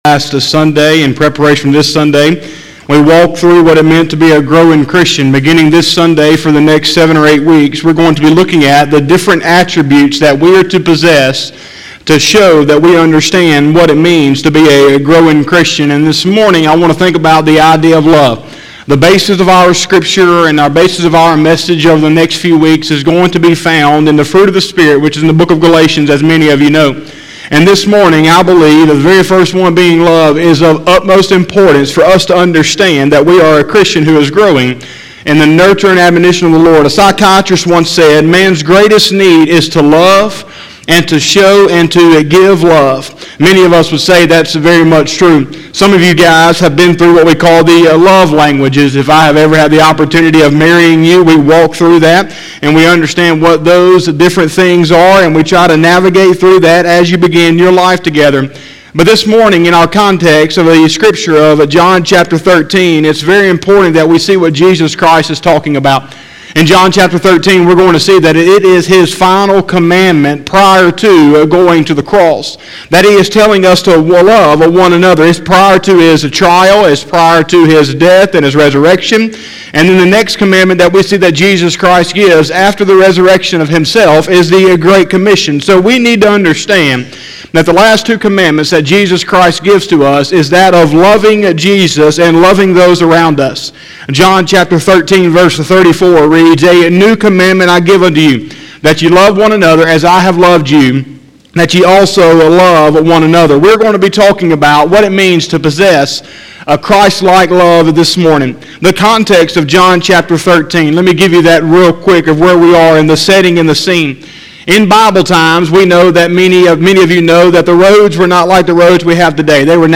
01/03/2021 – Sunday Morning Service